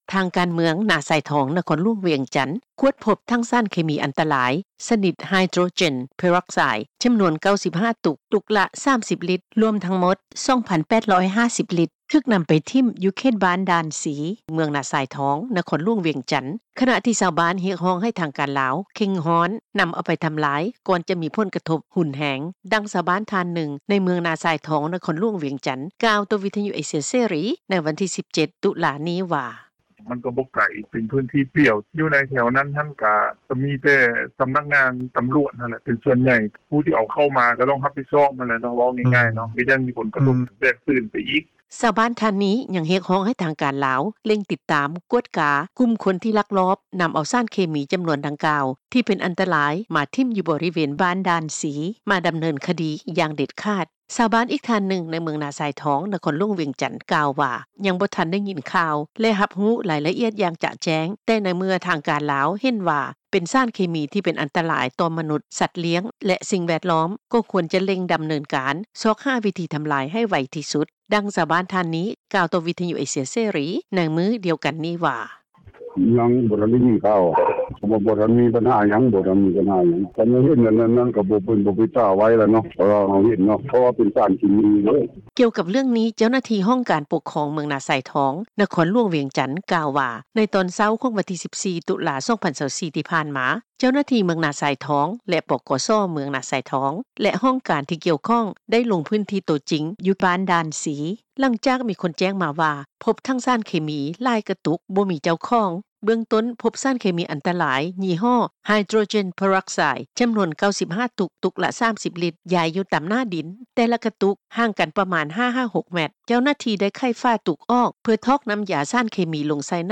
ດັ່ງ ຊາວບ້ານທ່ານໜຶ່ງ ໃນເມືອງນາຊາຍທອງ ນະຄອນຫຼວງວຽງຈັນ ກ່າວຕໍ່ວິທຍຸເອເຊັຽເສຣີ ໃນມື້ວັນທີ 17 ຕຸລາ ນີ້ວ່າ:
ດັ່ງ ເຈົ້າໜ້າທີ່ທ່ານນີ້ ກ່າວຕໍ່ ວິທຍຸເອເຊັຽເສຣີ ໃນມື້ດຽວກັນນີ້ວ່າ: